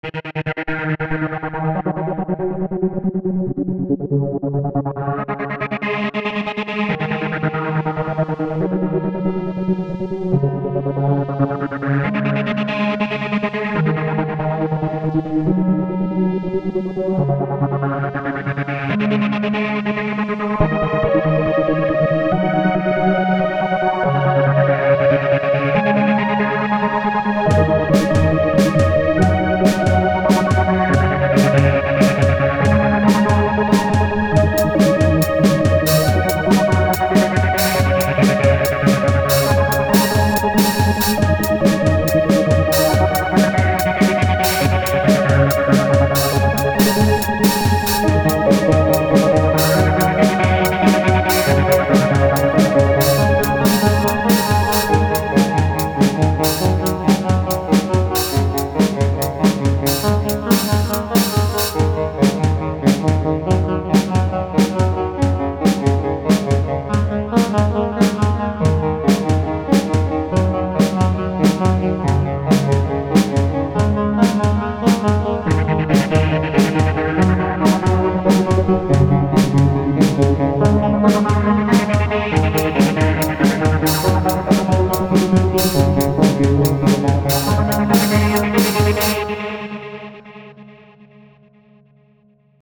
It makes for a very good ambient effect, and the drums starting up really gives me the impression of things heating up. The ambient sounds make it seem alien and desolate.